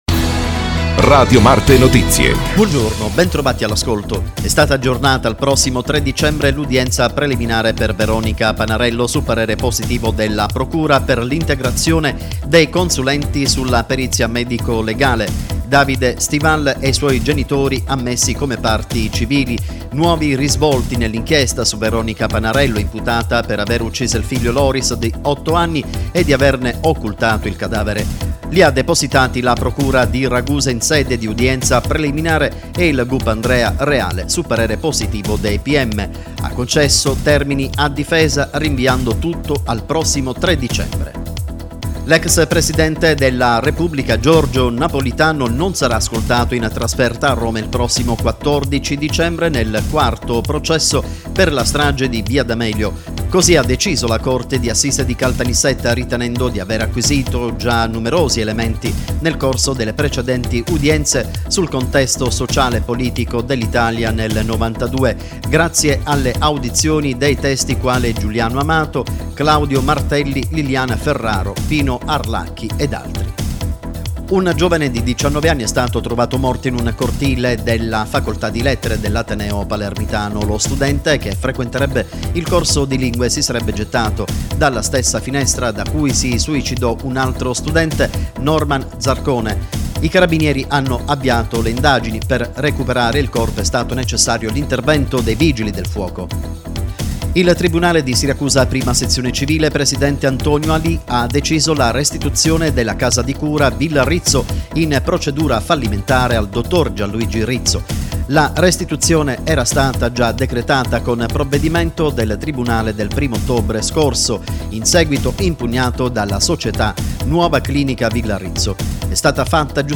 Intervista al Sindaco di Portopalo 21/11/2015